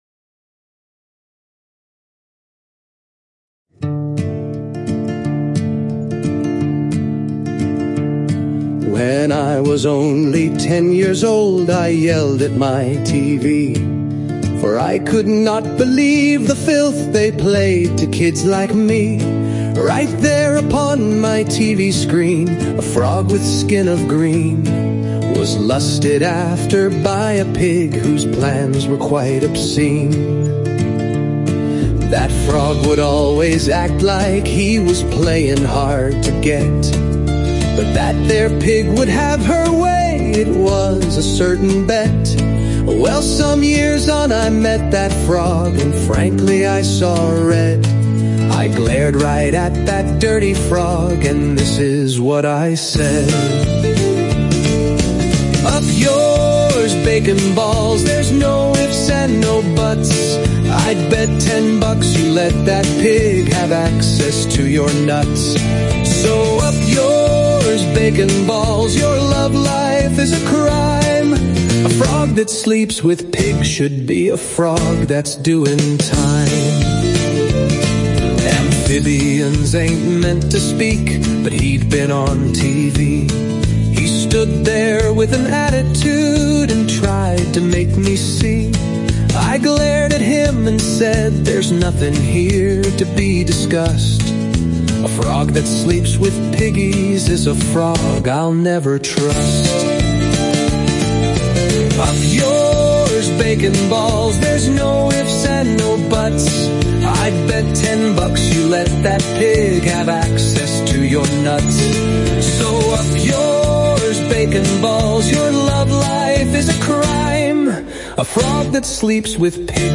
Music by Bits & Bytes (AI)